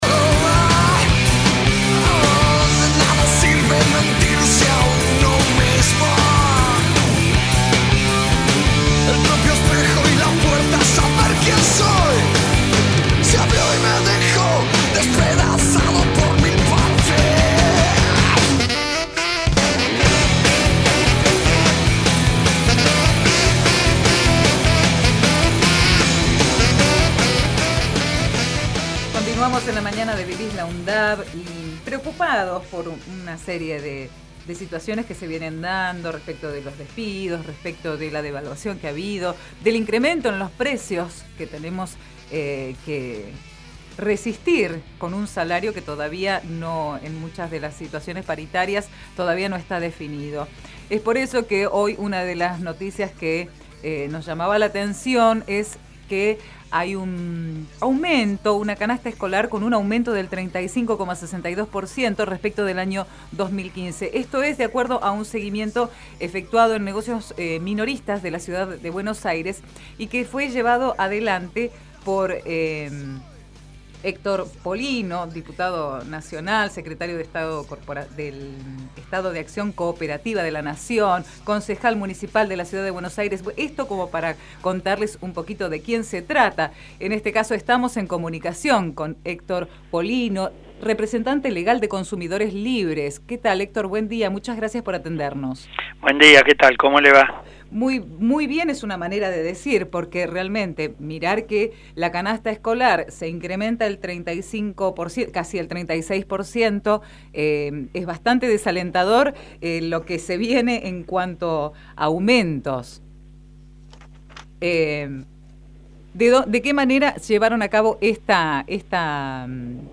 Consumidores Libres convocó a un boicot a la compra de carne Texto de la nota: La asociación llamó a no comprar carne debido a las injustificadas subas de precios y realizó una medición que señala un 35% de aumento en el valor de la canasta escolar. Entrevistamos al Dr. Héctor Polino, fundador y representante legal de la asociación Consumidores Libres. Archivo de audio: 2016-02-05 HECTOR POLINO DEF CONSUMIDOR.mp3 Programa: Vivís la UNDAV